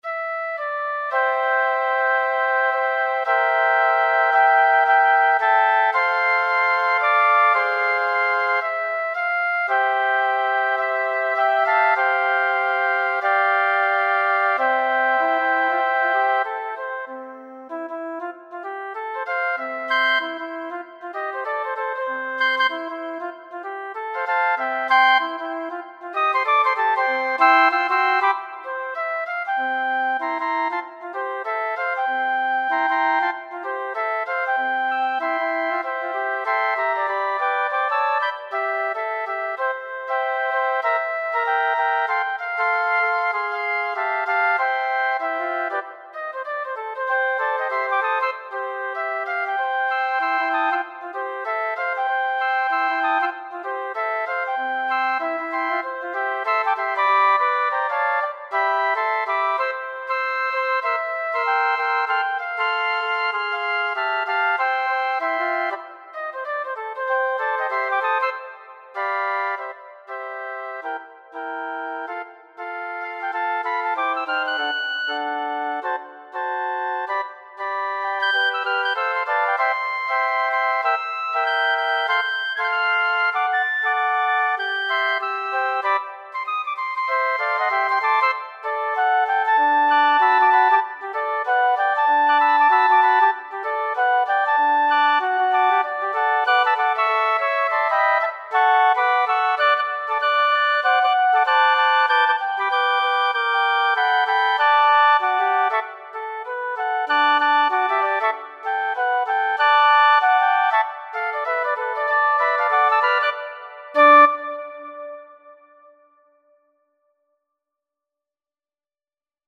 Scored for Flute Quartet.
Spirituals